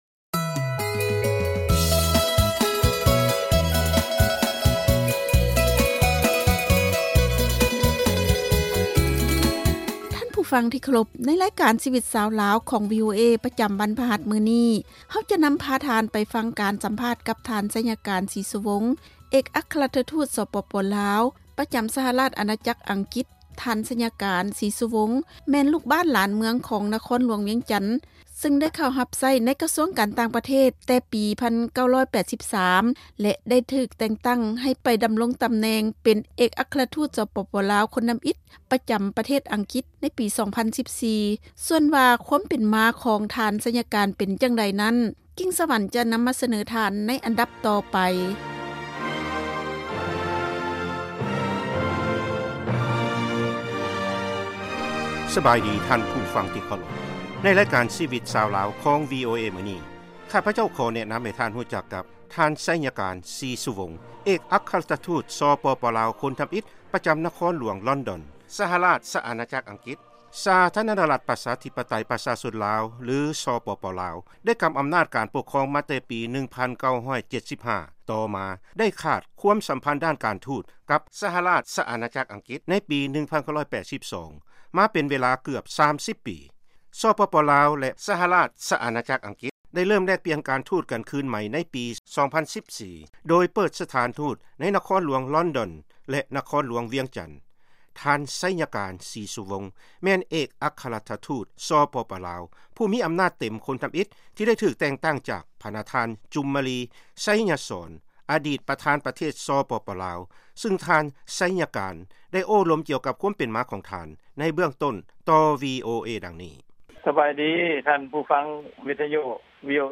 ວີໂອເອ ສຳພາດ ທ່ານໄຊຍະການ ສີສຸວົງ ເອກອັກຄະລັດຖະທູດ ສປປ ລາວ ປະຈຳ ສະຫະລາດຊະອານາຈັກ ອັງກິດ
ວີໂອເອ ຈະນໍາພາທ່ານໄປຟັງການສຳພາດກັບ ທ່ານໄຊຍະການ ສີສຸວົງ ເອກອັກຄະລັດຖະທູດ ສປປ ລາວ ປະຈຳ ສະຫະລາດຊະອານາຈັກອັງກິດ ທີ່ນະຄອນຫລວງລອນດອນ.